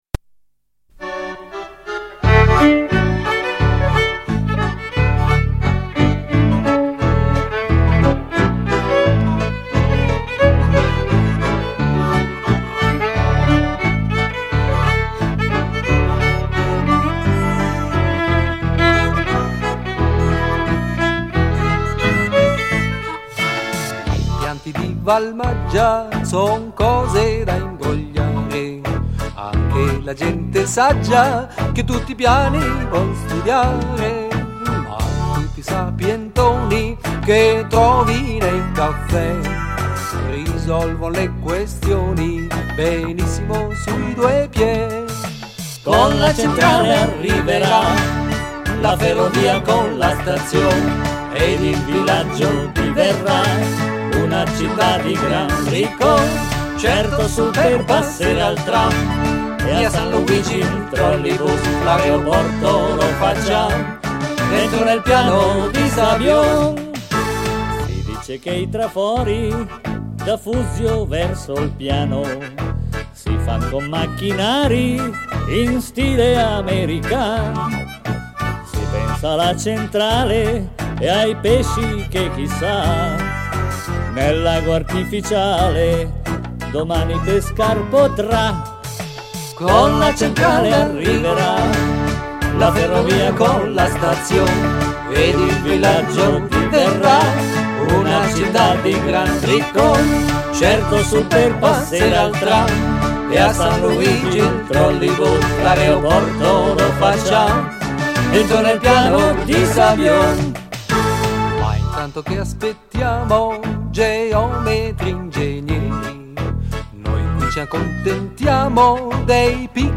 E noi? Noi saremo al Ristorante La Pernice, a Cancorì, pronti a raccontarvi di questo sport, ma non solo, vi parleremo di tutto quanto ruota intorno al comprensorio del Nara.